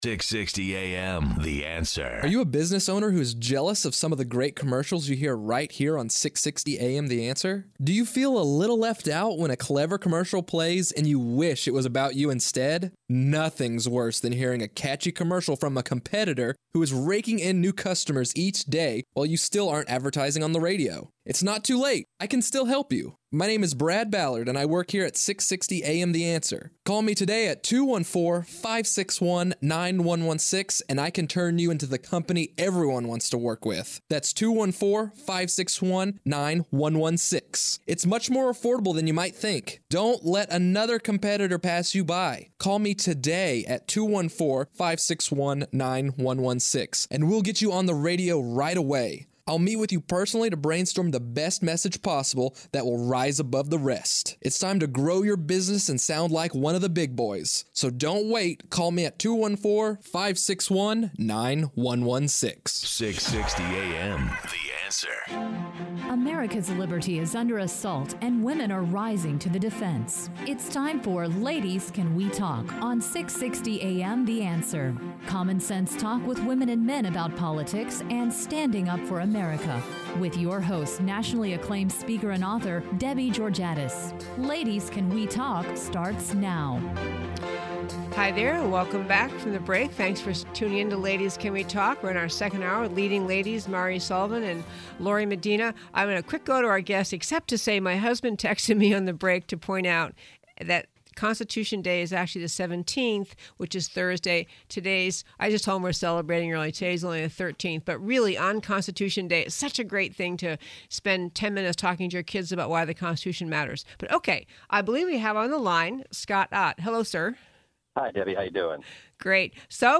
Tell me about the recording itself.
Listen to the podcast from the second hour of our September 13th show on 660AM.